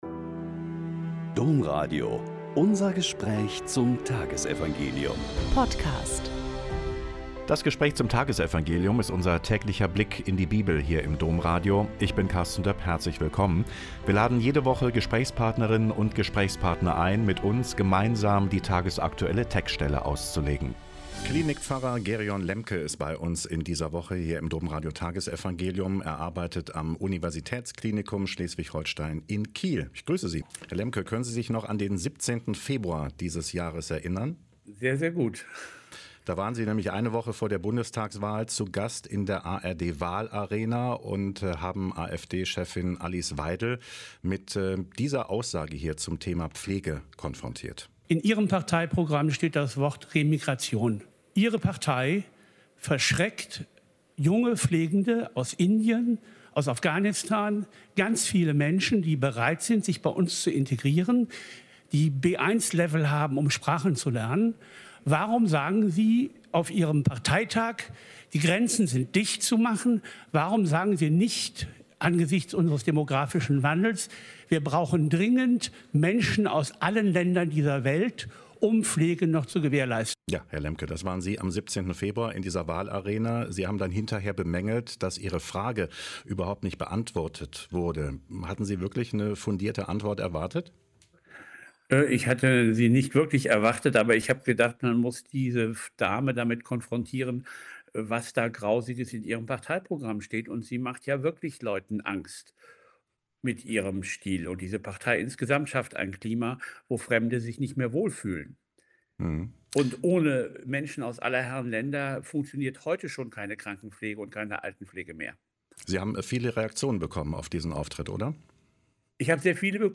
Joh 14,1-6 - Gespräch